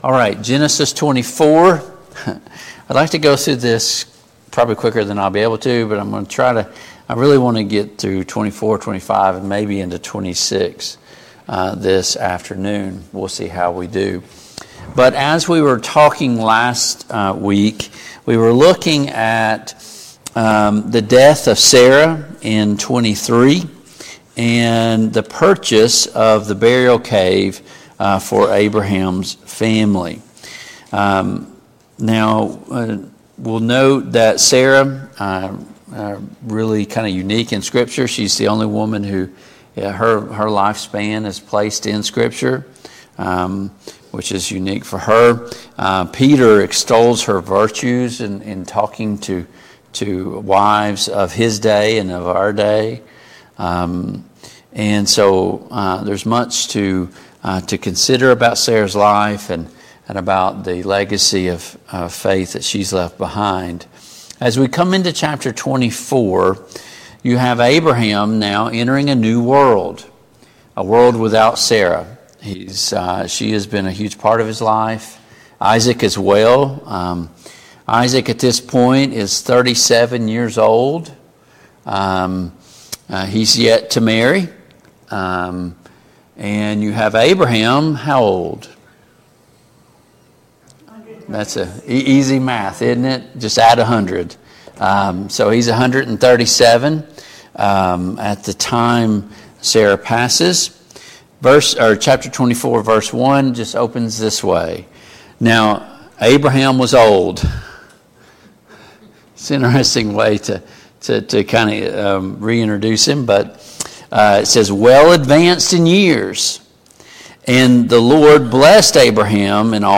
Genesis 24 Service Type: Family Bible Hour Topics: Isaac and Rebekah « How do I hit the reset button?